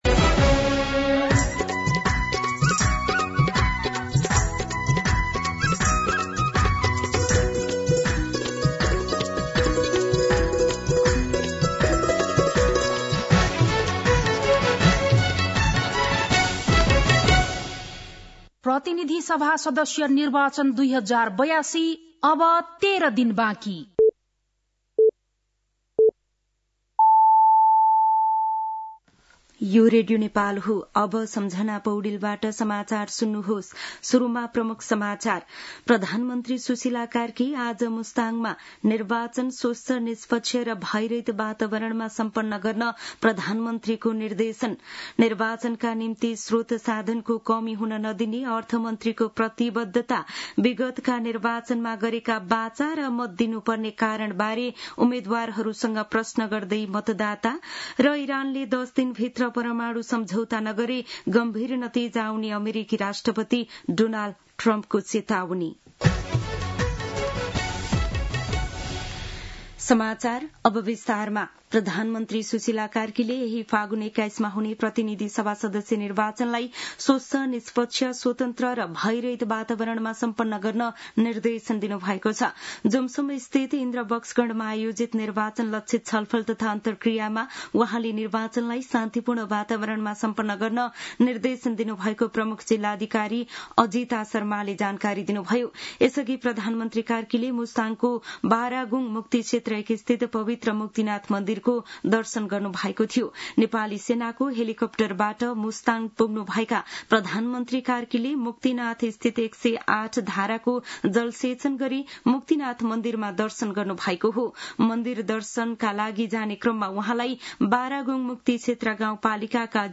दिउँसो ३ बजेको नेपाली समाचार : ८ फागुन , २०८२